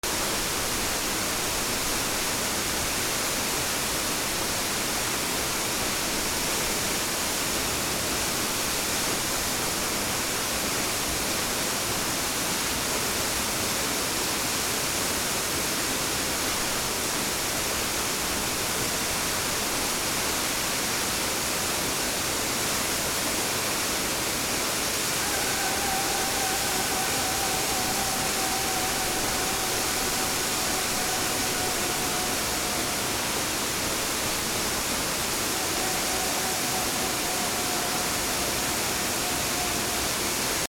1 滝 ザー 展望台から
/ B｜環境音(自然) / B-15 ｜水の流れ